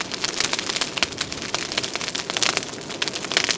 Fire.wav